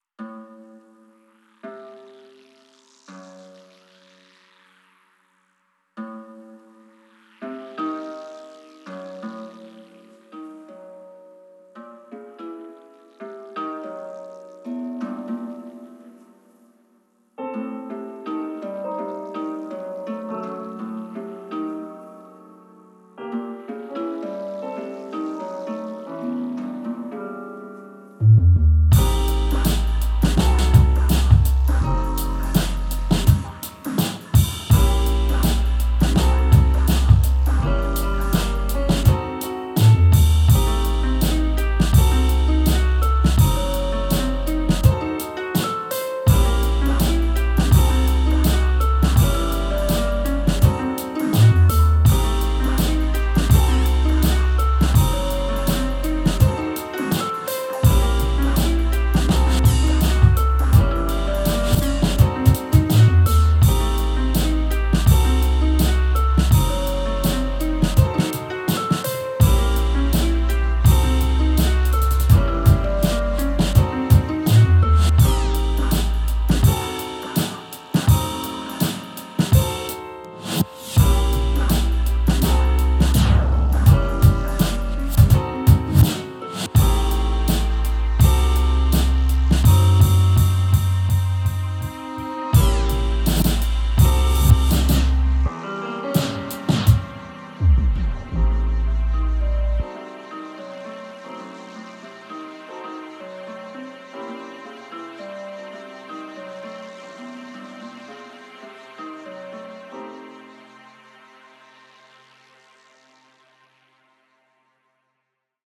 boom-bap spontané au casque car mon studio est en vrac